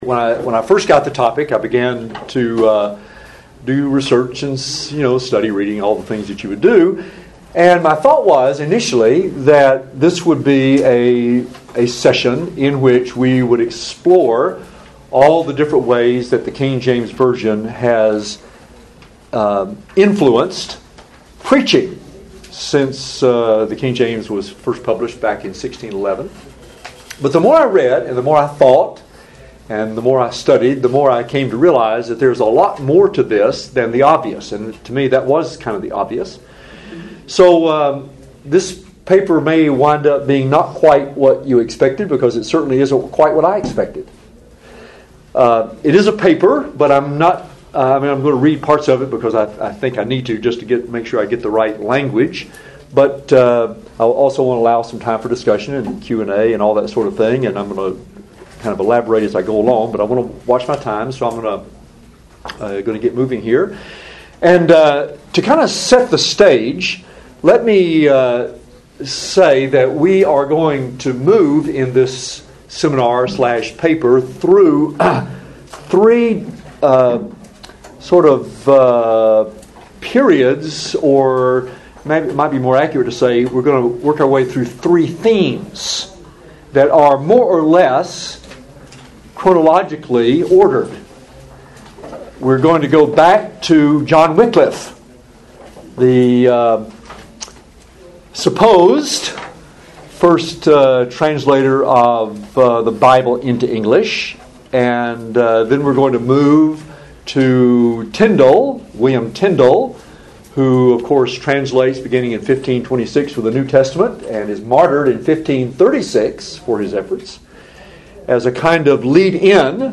KJV400 Festival
Union University Address: From Wycliff to Bible Code: How Preaching Created the KJV . . . And What Happened Then